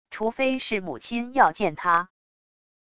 除非是母亲要见他wav音频生成系统WAV Audio Player